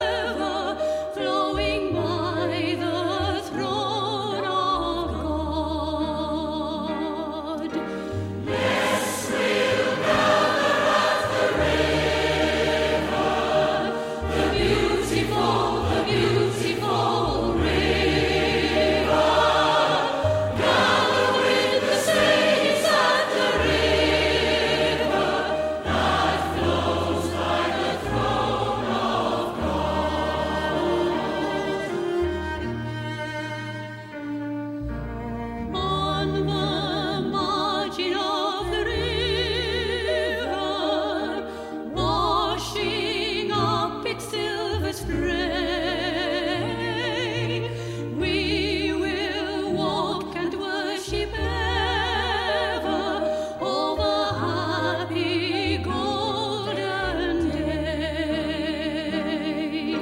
Praise & Worship